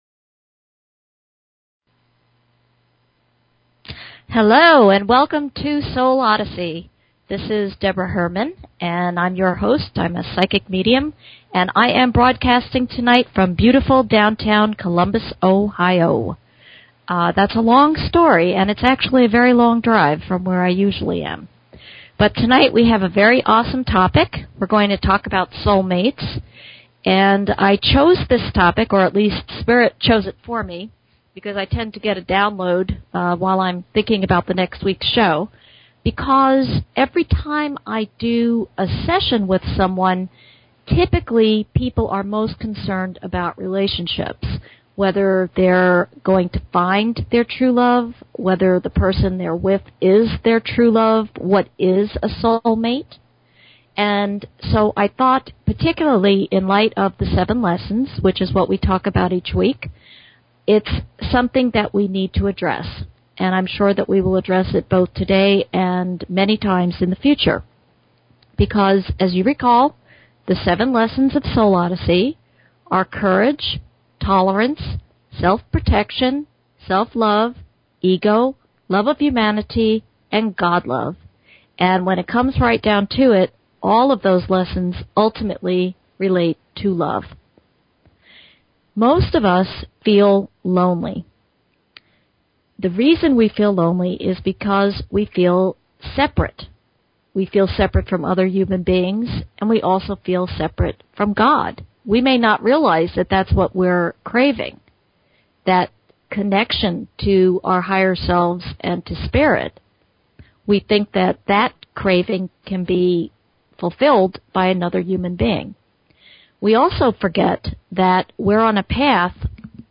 Talk Show Episode, Audio Podcast, Soul_Odyssey and Courtesy of BBS Radio on , show guests , about , categorized as
This week's radio show topic will be "soulmates." Remember to call in with your questions.